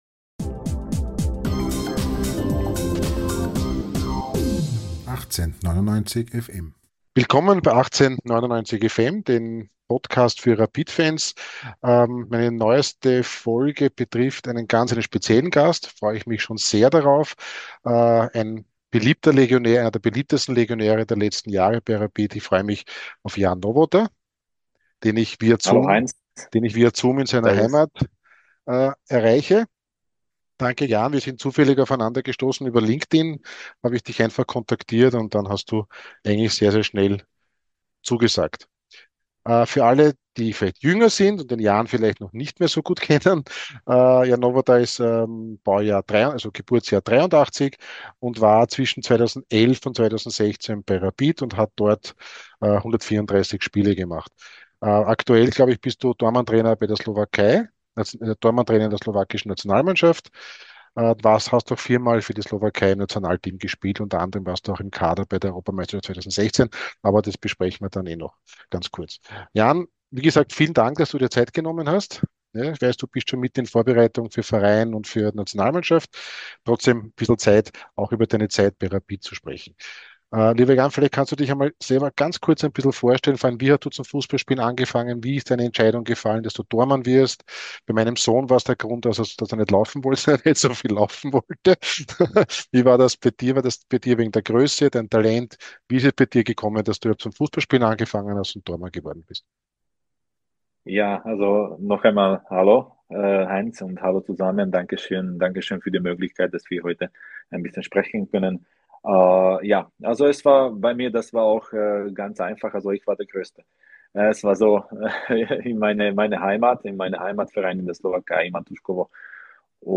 1899FM - Folge 111 - Hauptsache Rapid gewinnt - Im Gespräch